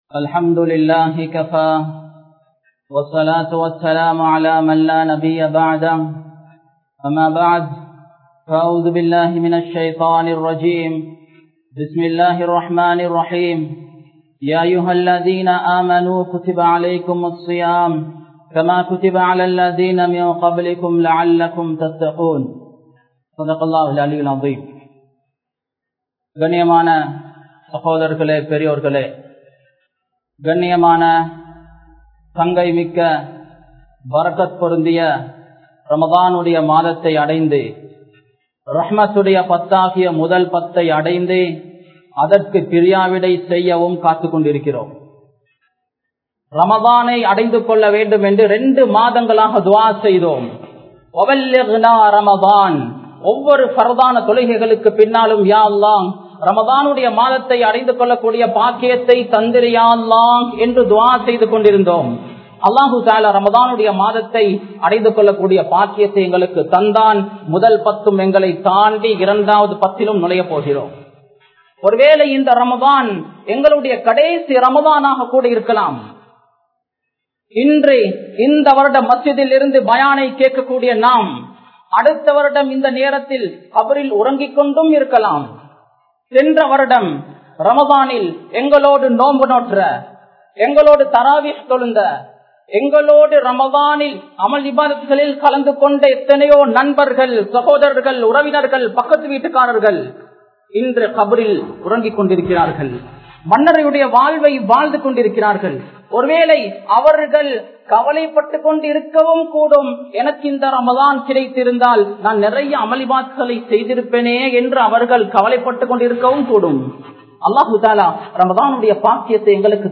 Ihlas & Amalhal (இஹ்லாஸ் & அமல்கள்) | Audio Bayans | All Ceylon Muslim Youth Community | Addalaichenai
Gampola, Singhapitiya Masjidh